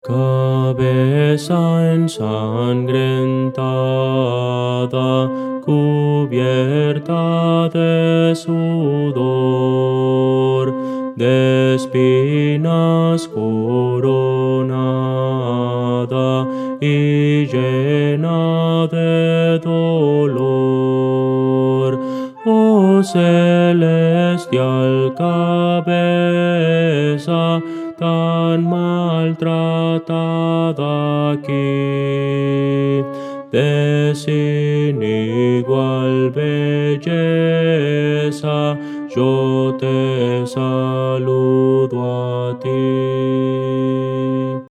Voces para coro
Contralto
Audio: MIDI